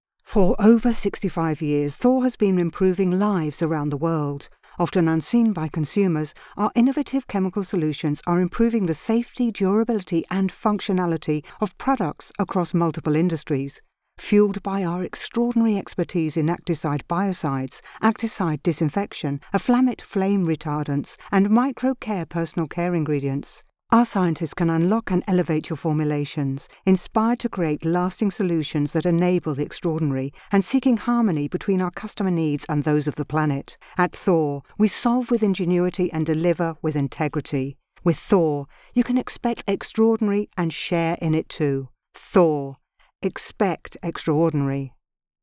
A calm, educated and believable tone. Authentic and natural.
Commercial - THOR
British Neutral/RP